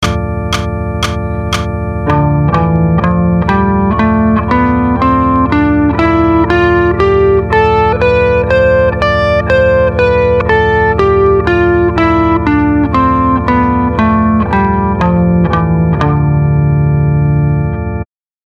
For example if you play the C major scale starting on D you are playing the D Dorian mode.
D Dorian | Download
d_dorian.mp3